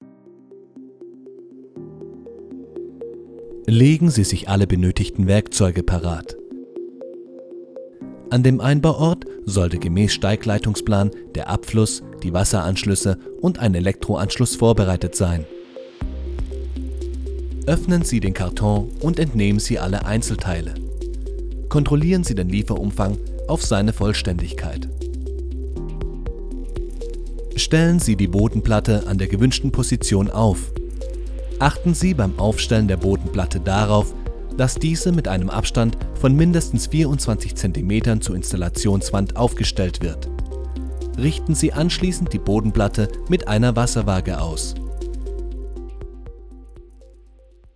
Voiceover & Sprecher
Als Sprecher für Voiceover, Werbung, Imagefilme, Eventvideos, Hörbücher und Hörspiele bringe ich Inhalte authentisch und emotional auf den Punkt – ruhig oder dynamisch, seriös oder lebendig, passend zu deinem Projekt.